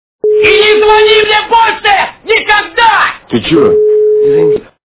» Звуки » Люди фразы » И не звони мне - больше никогда!
При прослушивании И не звони мне - больше никогда! качество понижено и присутствуют гудки.